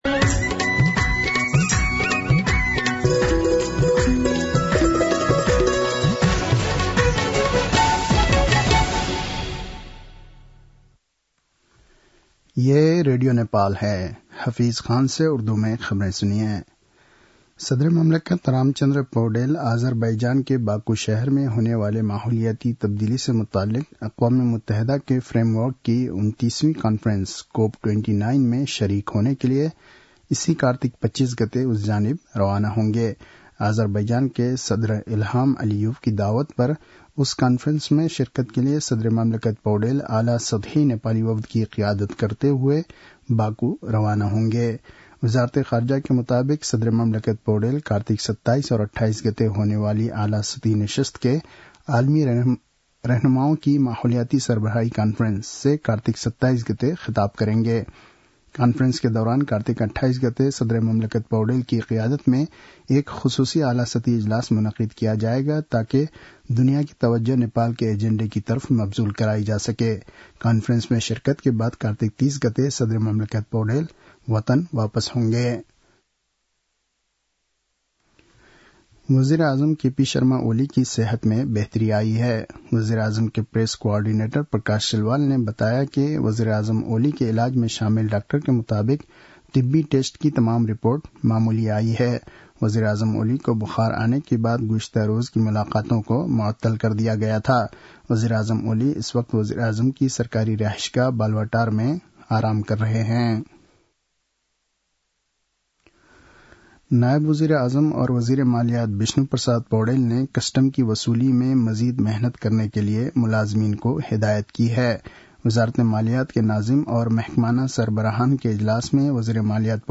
An online outlet of Nepal's national radio broadcaster
उर्दु भाषामा समाचार : २२ कार्तिक , २०८१